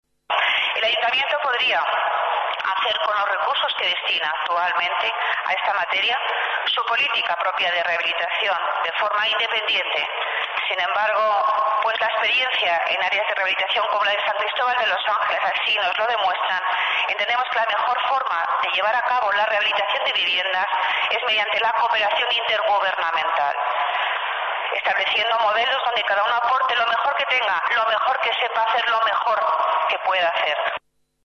Nueva ventana:Declaraciones de Pilar Martínez, delegada de Urbanismo